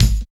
101 KICK 3.wav